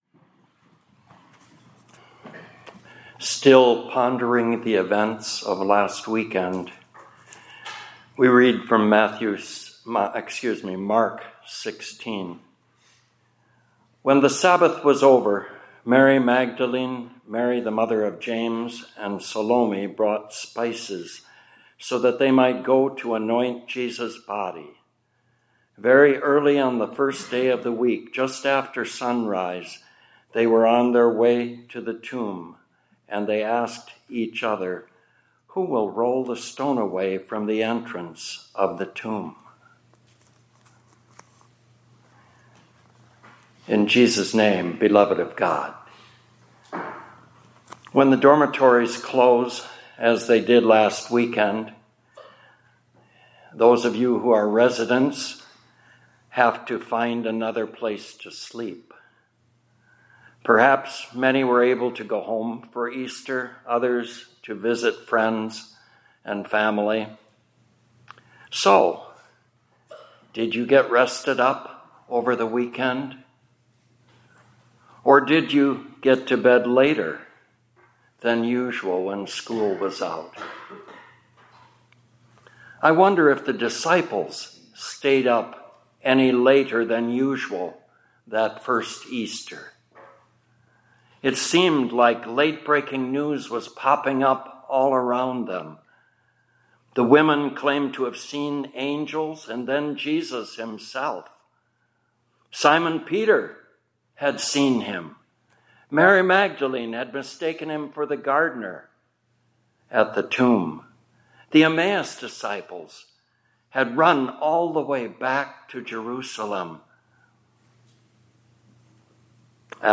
2026 Chapels -